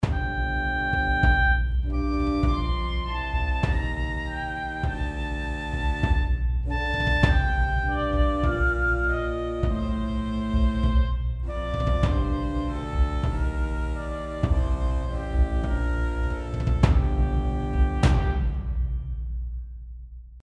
Oboe und Kontrafagott, beide mit der Spielweise Legato (die echte Legato-Samples verwendet), dazu ein paar Schläge aus Native Instruments Action Strikes (Big Toms).
Während das Kontrafagott in den tiefen Lagen markante Akzente setzt, klingt die Oboe ausgesprochen lieblich. Beide Instrumente harmonieren wunderbar miteinander und schaffen einen interessanten Kontrast.